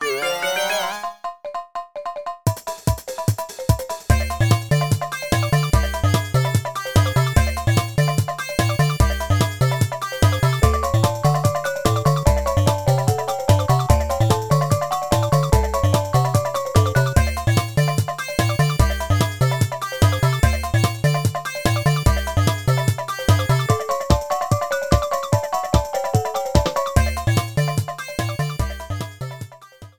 Shortened, fadeout